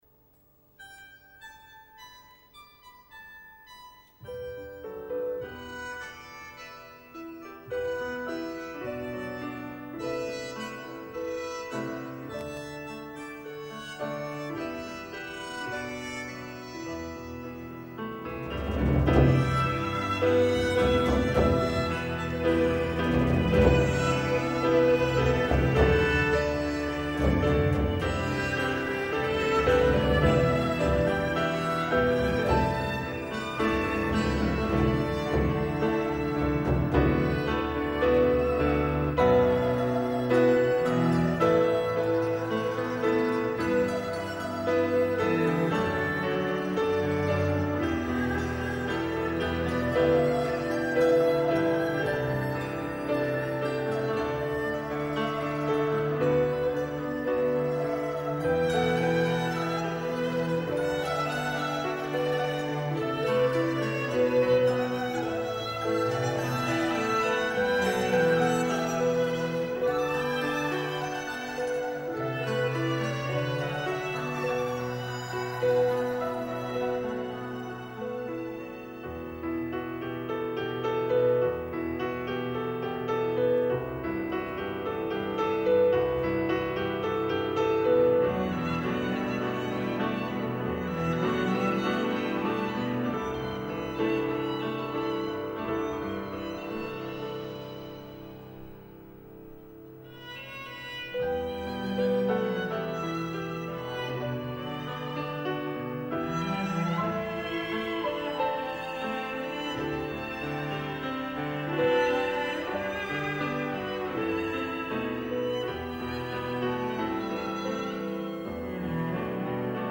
new score for the silent film